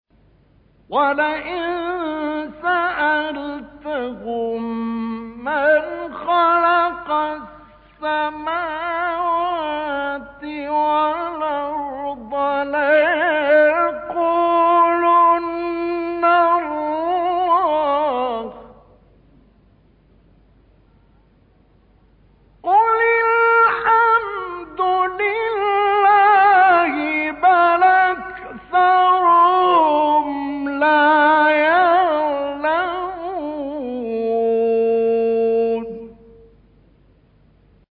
گروه شبکه اجتماعی: فرازهایی از تلاوت قاریان ممتاز مصری در زیر ارائه می‌شود.